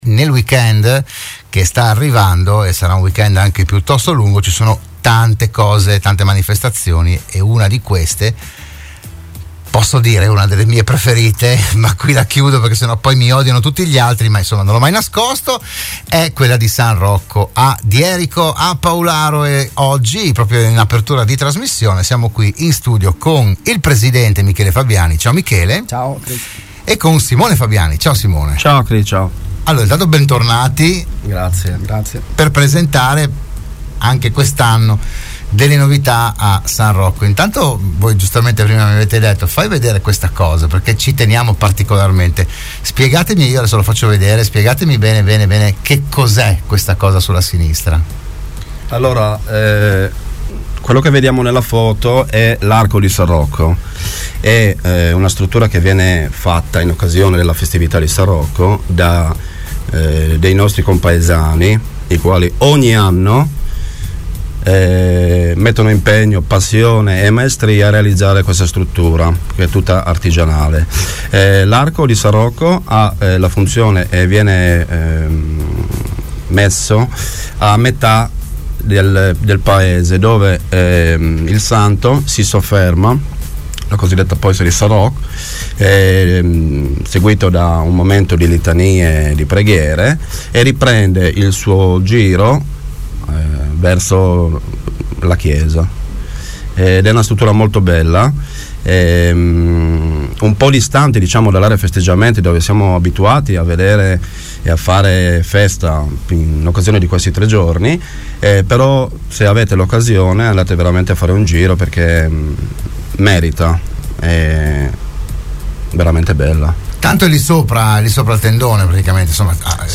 Dell’iniziativa si è parlato a “ RadioAttiva “, la trasmissione di Radio Studio Nord